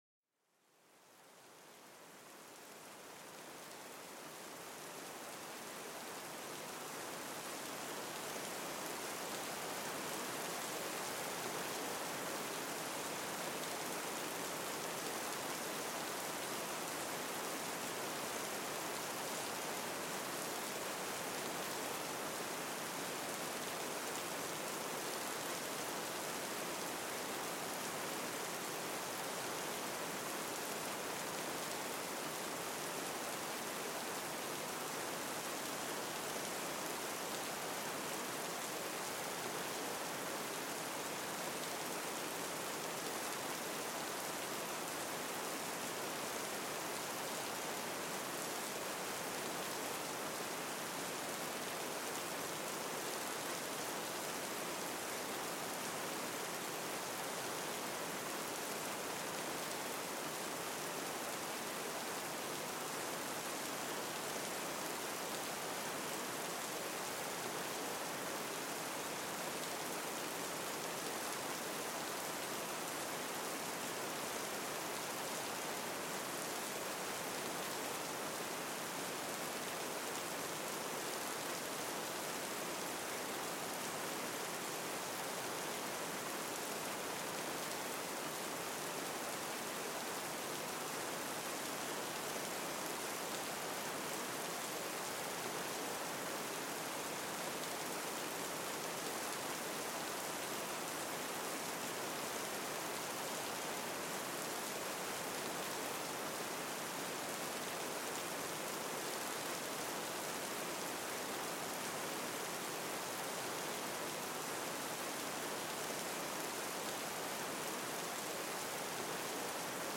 Pluie Battante: Une Douce Mélodie pour Apaiser l'Esprit
Découvrez le pouvoir apaisant d'une grosse averse de pluie dans cet épisode. Écoutez le rythme régulier et rassurant de la pluie qui frappe la terre, créant une symphonie naturelle.